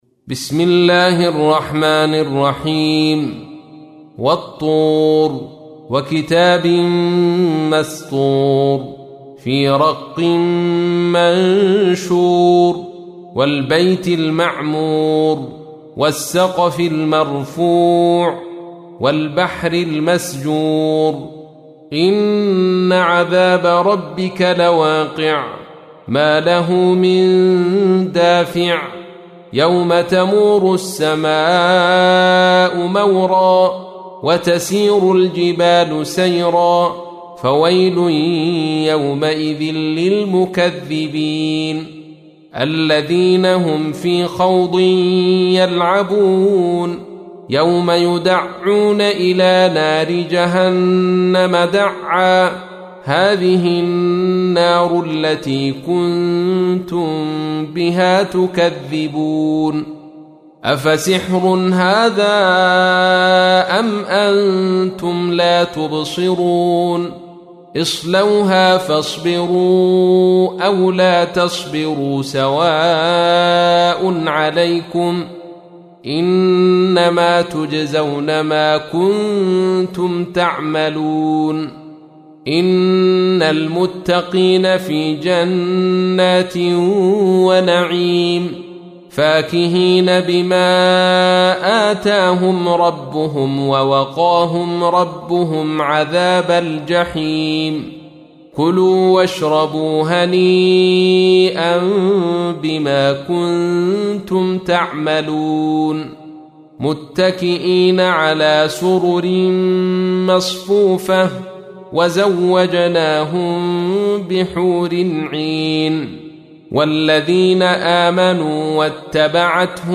تحميل : 52. سورة الطور / القارئ عبد الرشيد صوفي / القرآن الكريم / موقع يا حسين